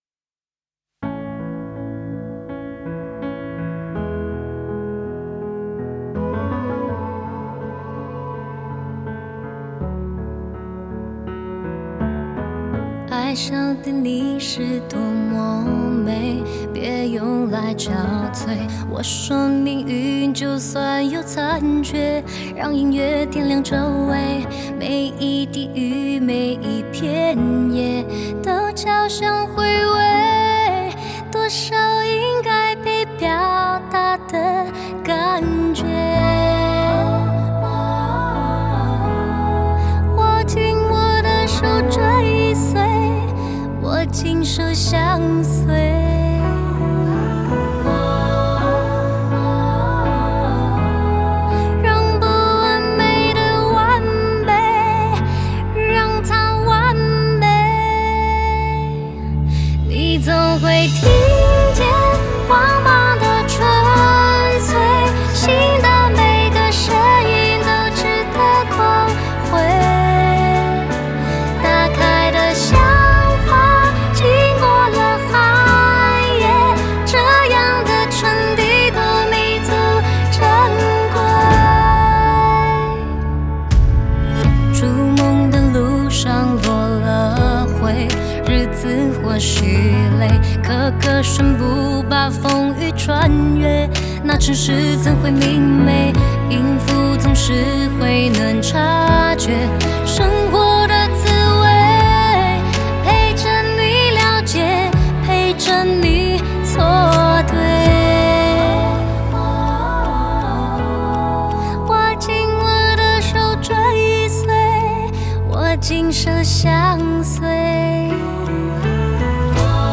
Ps：在线试听为压缩音质节选，体验无损音质请下载完整版
吉他
和音
弦乐团